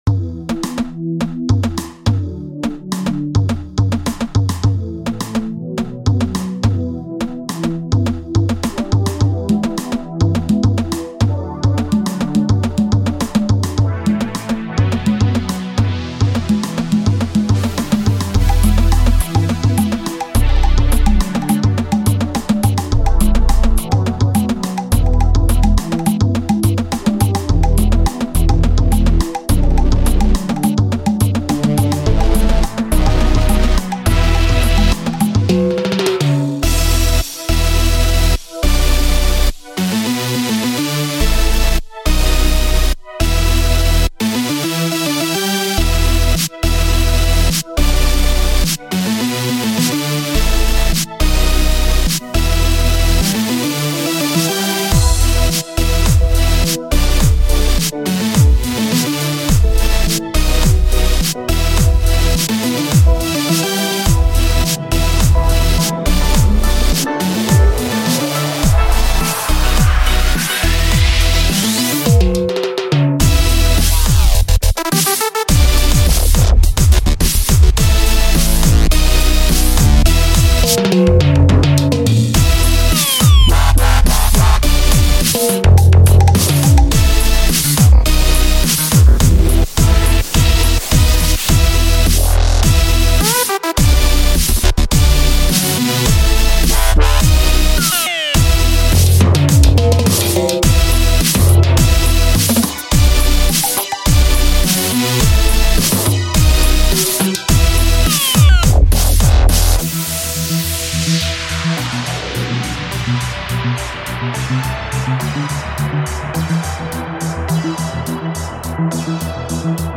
(indecipherable drums) BEEEEE!
(More drums) BEEE!